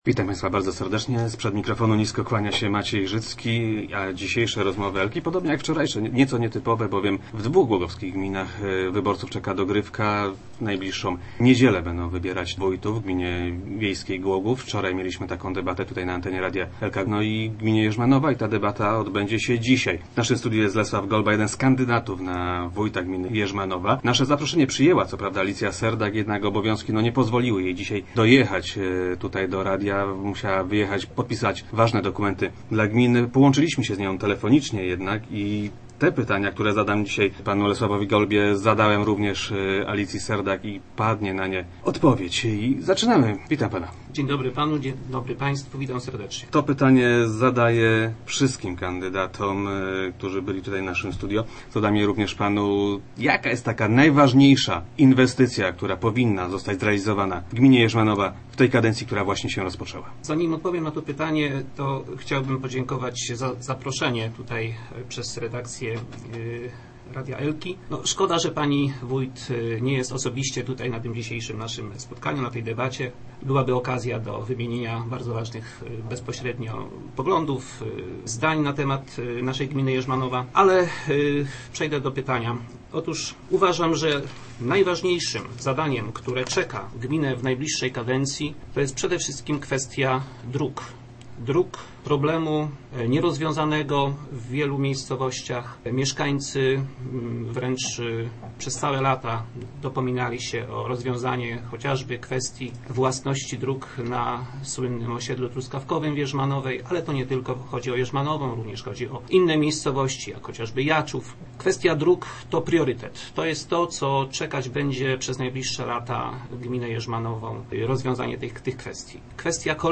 Kandydatów zaprosiliśmy do udziału w debacie w piątkowych Rozmowach Elki.